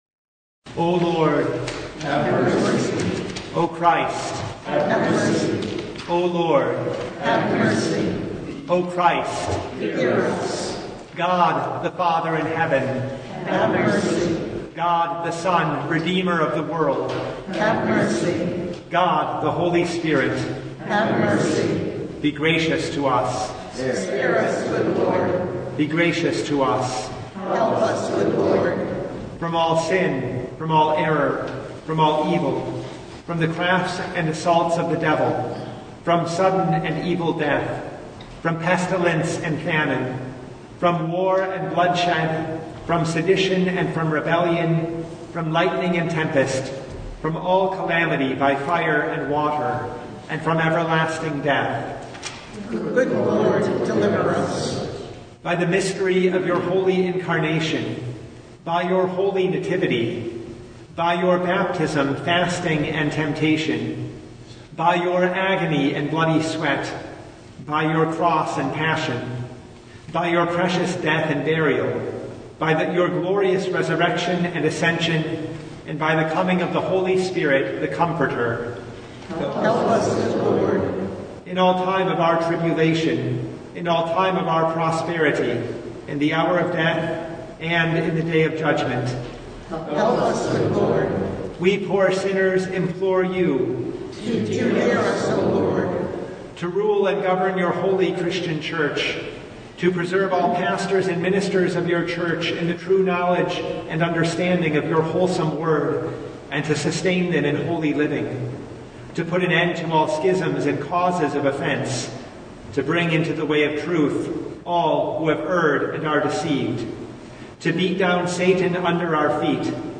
Passage: John 2:13-22 Service Type: Lent Midweek Noon
Full Service